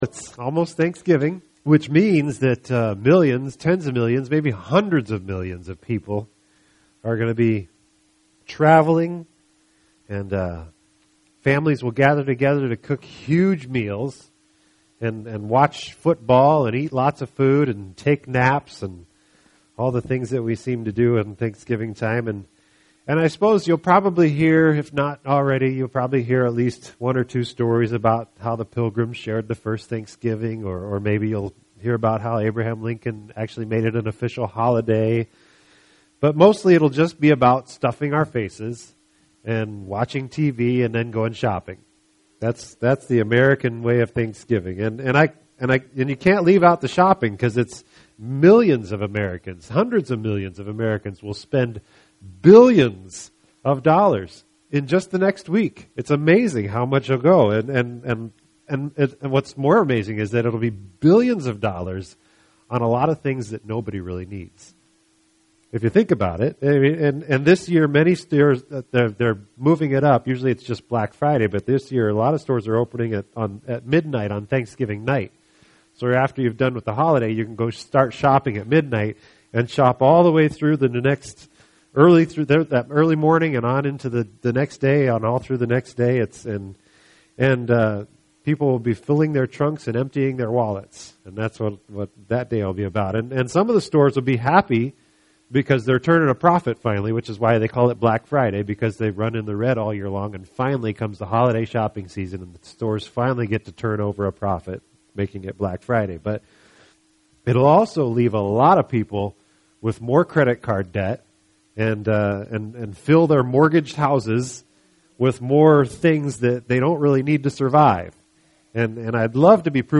This entry was posted on Wednesday, October 31st, 2012 at 1:23 am and is filed under Sermons.